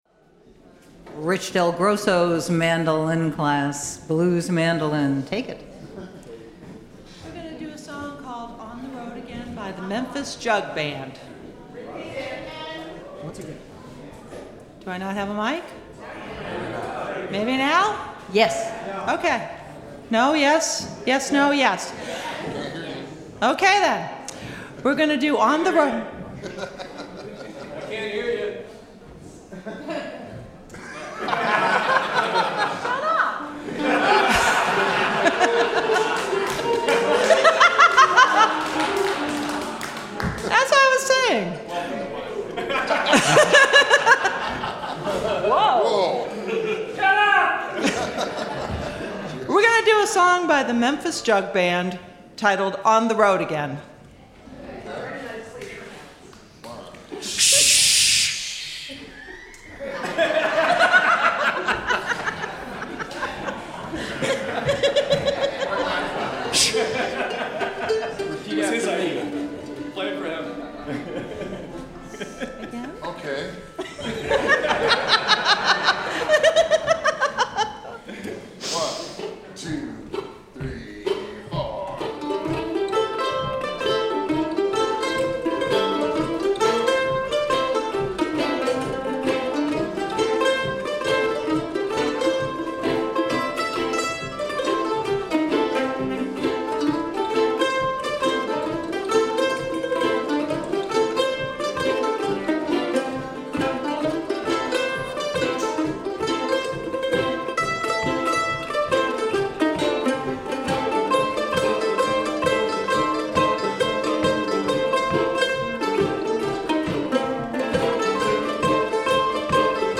Blues Mandolin